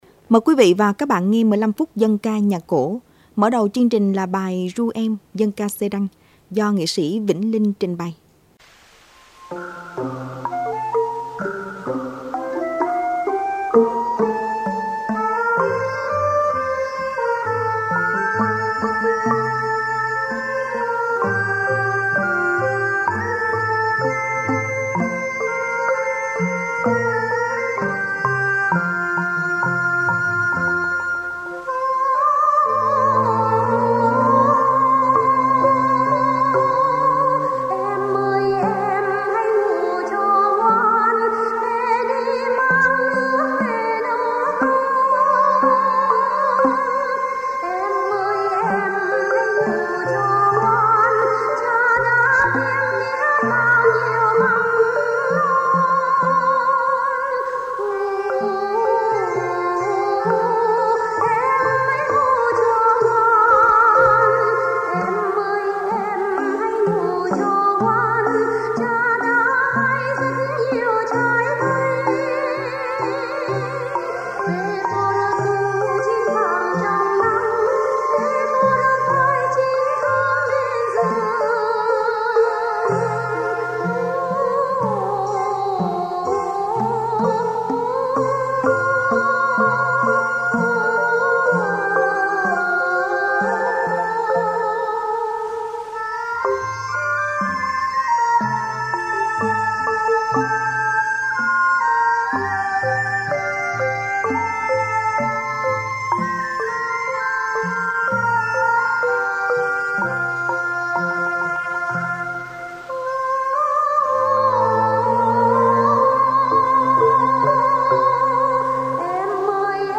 6-2-dan-ca-nhac-co.mp3